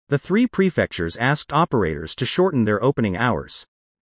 【ノーマル・スピード】